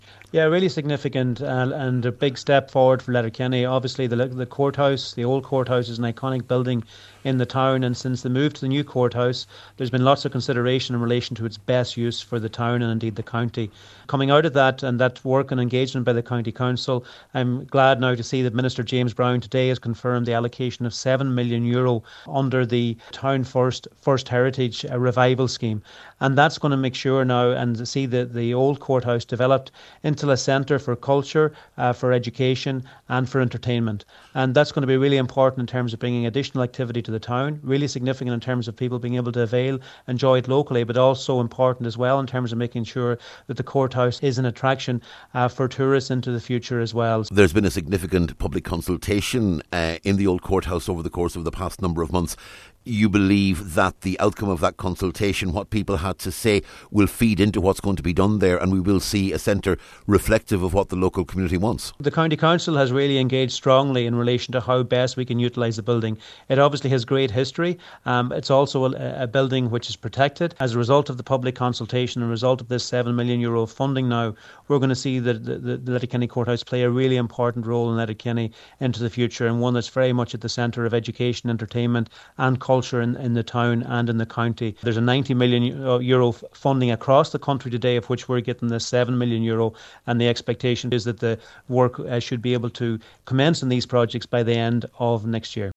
Minster Charlie McConalogue says the development of the former courthouse will be significant for Letterkenny: